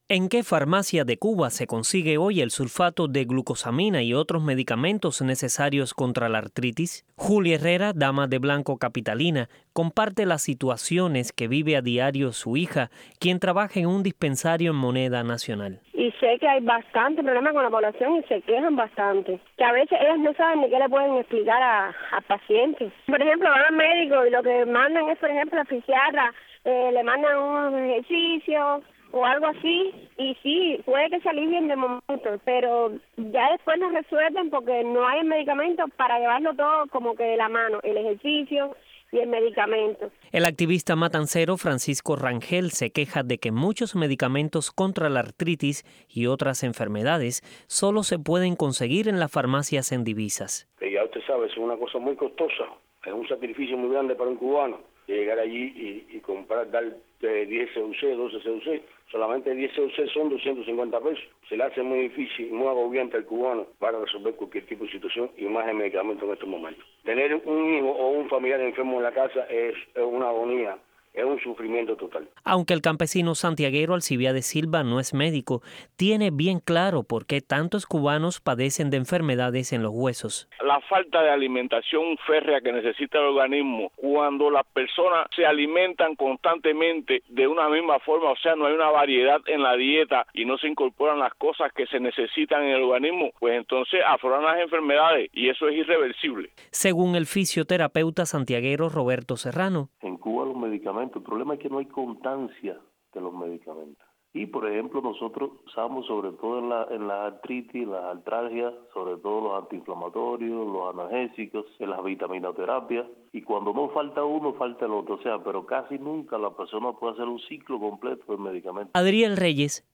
recoge opiniones sobre el tema